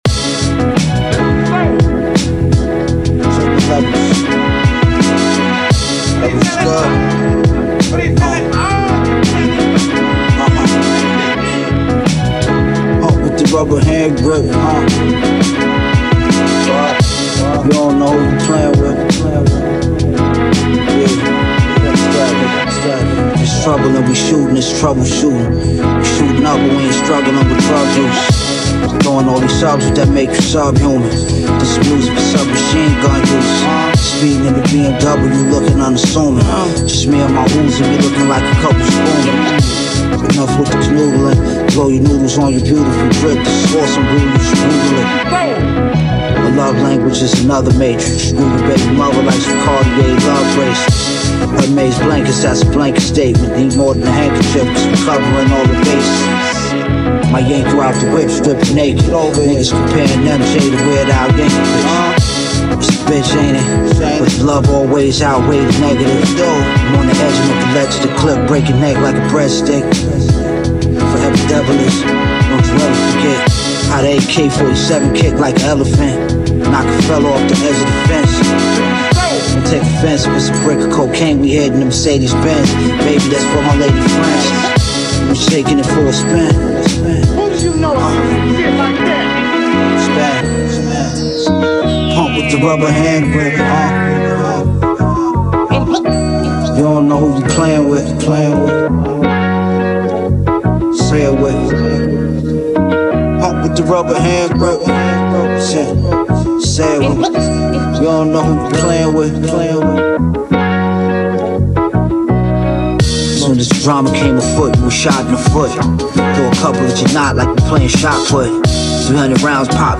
Some chops, a drum break and an acapella.